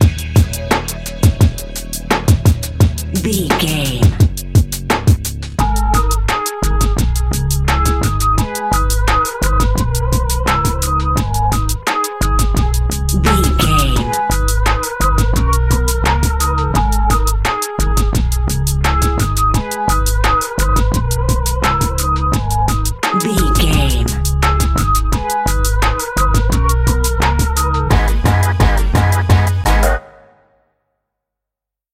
Aeolian/Minor
G#
drum machine
synthesiser
funky